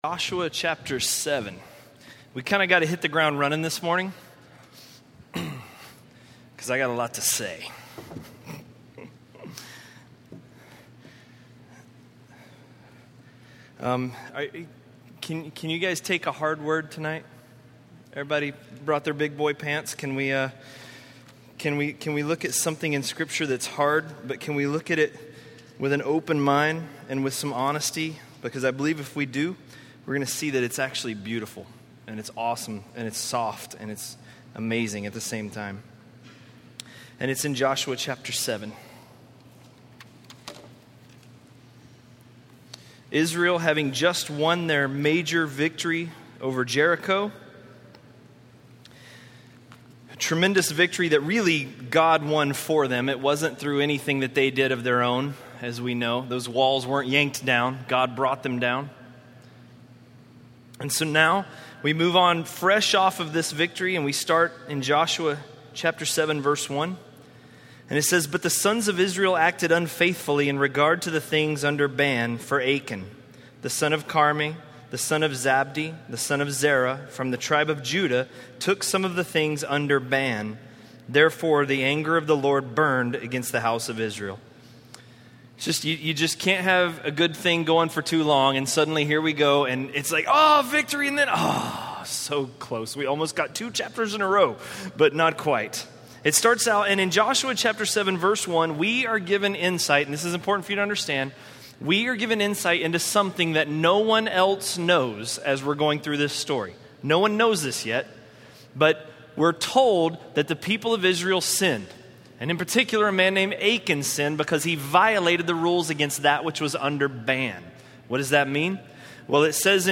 A message from the series "(Untitled Series)." by